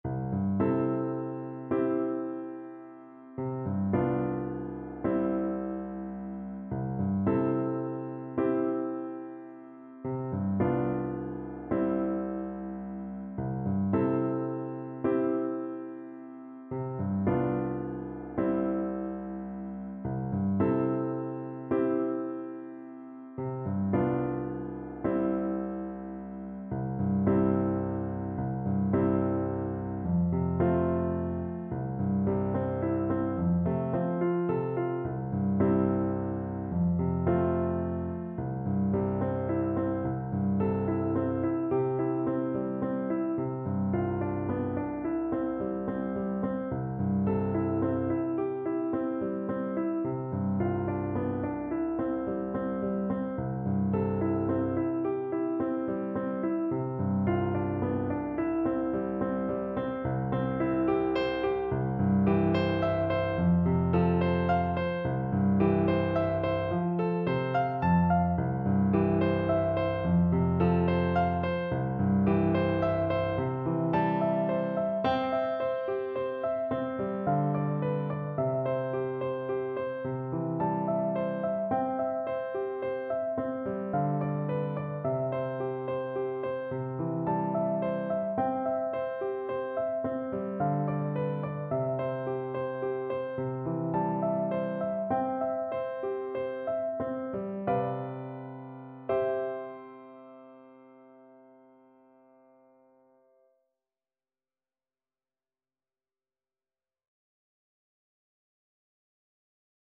Flute
C major (Sounding Pitch) (View more C major Music for Flute )
6/8 (View more 6/8 Music)
Gently .=c.48
Traditional (View more Traditional Flute Music)
South African